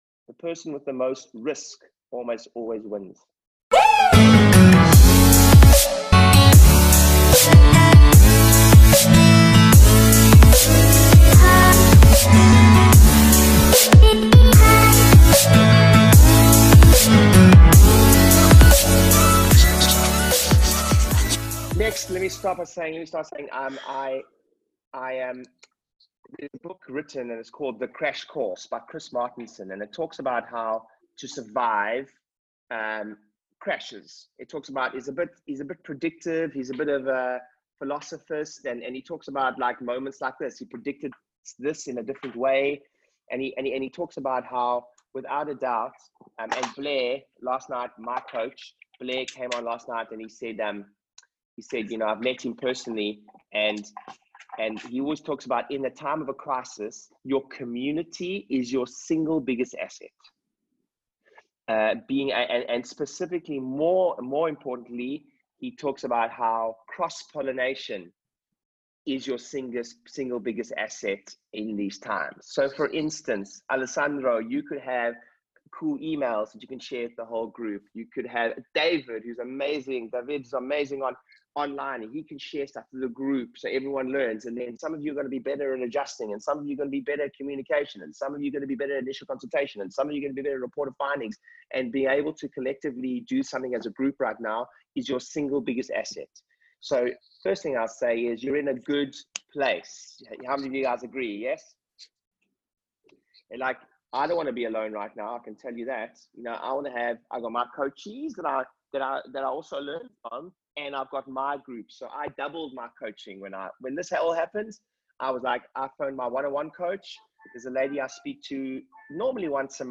A couple of weeks ago I jumped on a call with a good friend of mine and her coaching group to discuss some things that they can be doing right now to get through the current climate!